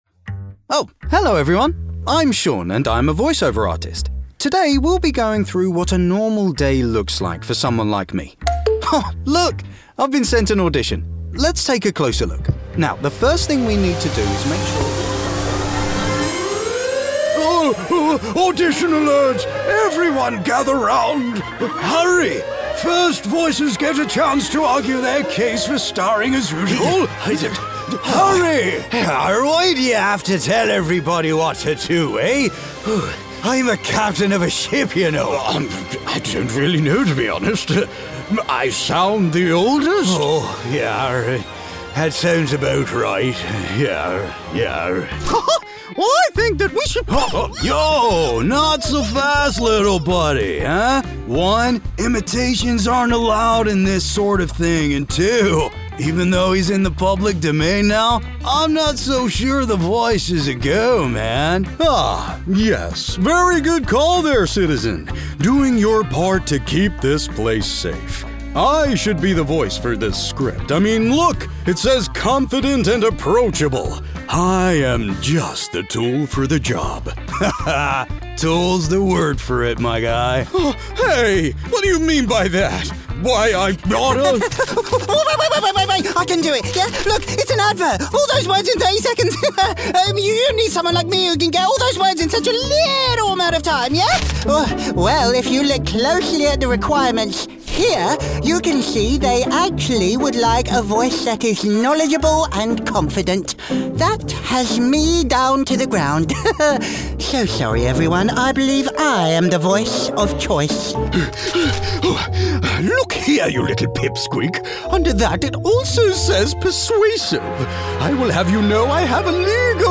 English (British)
Commercial, Deep, Natural, Distinctive, Playful